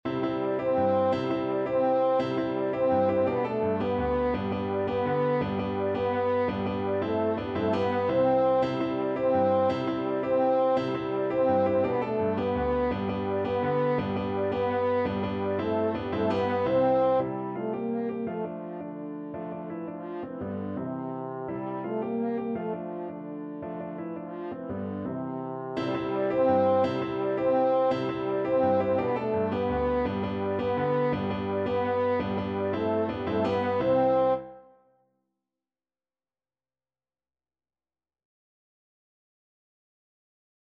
Fast and energetic =c.168
3/4 (View more 3/4 Music)
world (View more world French Horn Music)